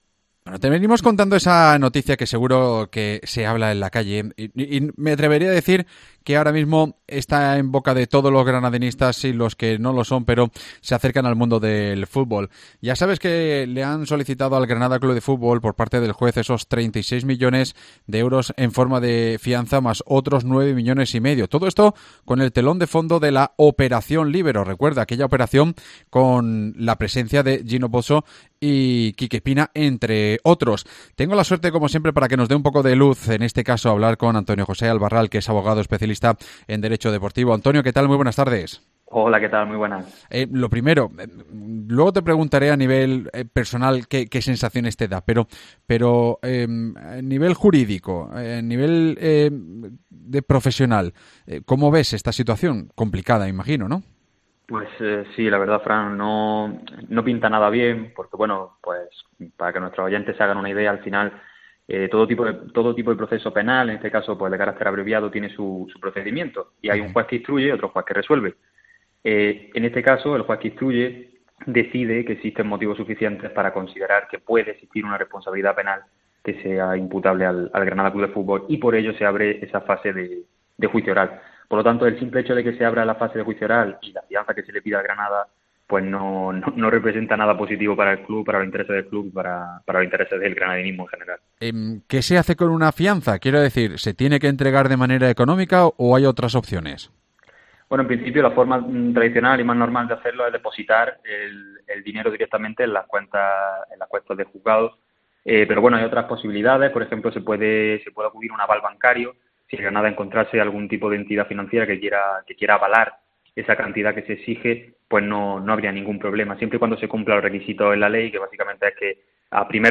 Hablamos con